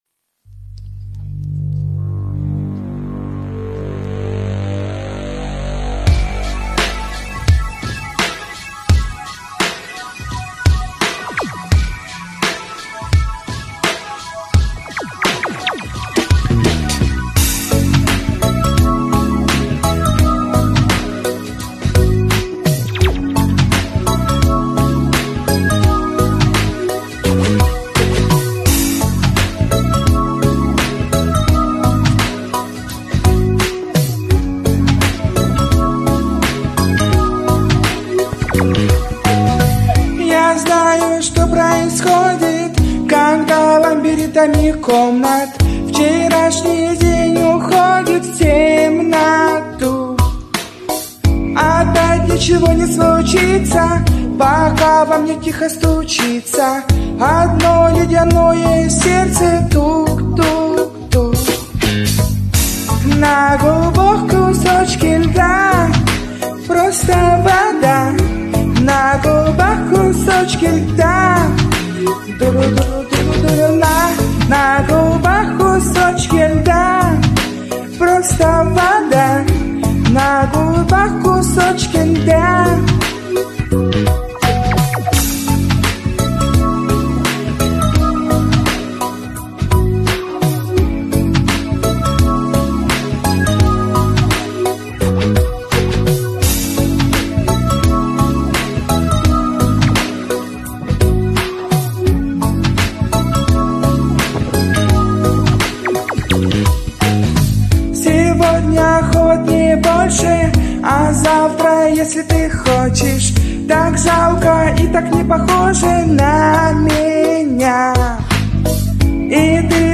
Тенор